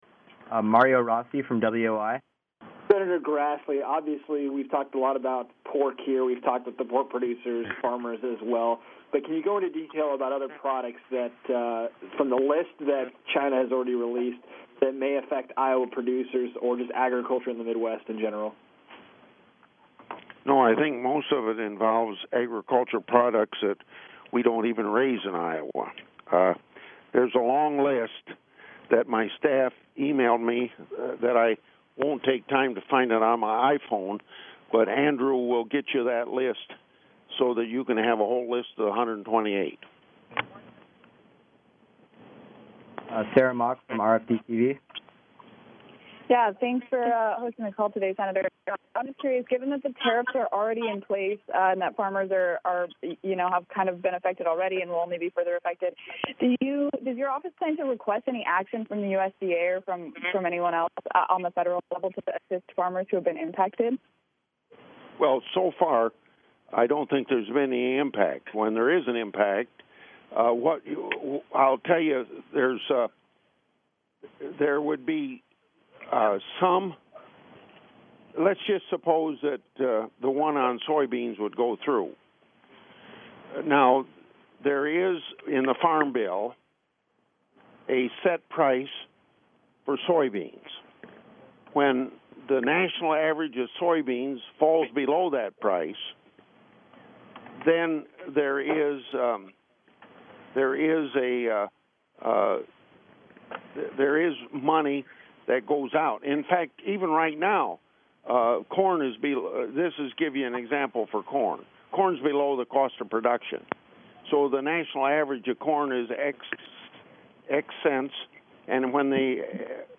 Conference Call on Asia Co-Del Part 2